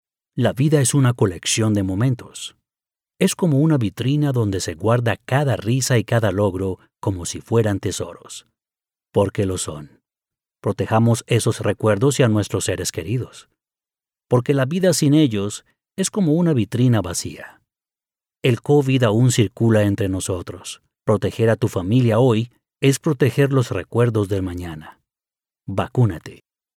Male
20s, 30s, 40s
Authoritative, Character, Cool, Corporate, Natural, Young, Bright, Engaging, Friendly, Smooth
Neutral, Latin American, Colombian, USA, Mexican neutral English with Hispanic / Latino accent
DRY READ DEMO-STUDIO QUALITY.mp3
Microphone: Neumann